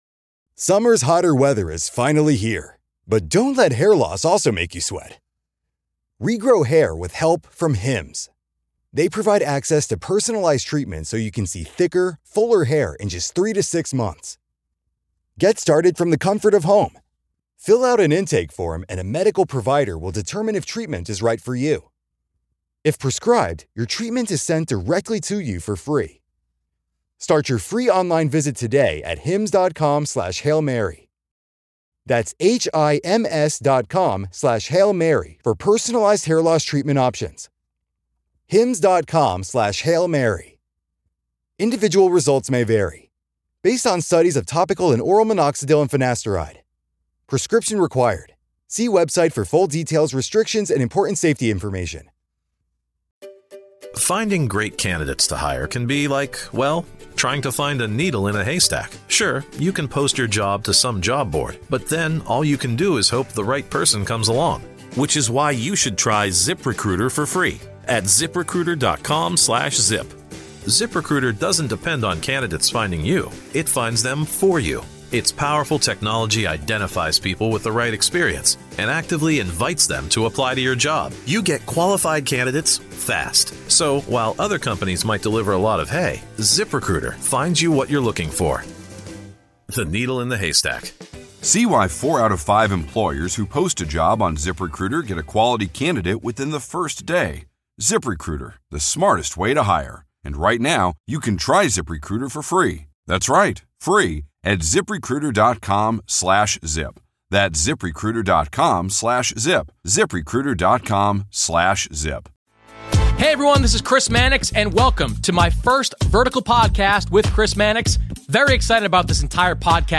The Crossover NBA Show SI NBA Basketball, Sports 4.6 • 641 Ratings 🗓 2 February 2016 ⏱ 64 minutes 🔗 Recording | iTunes | RSS 🧾 Download transcript Summary Chris Mannix heads to Los Angeles for a sit down interview with Paul Pierce of the Los Angeles Clippers. Paul & Chris have known each other for many years and The Vertical Podcast with Chris Mannix debuts with this in depth interview.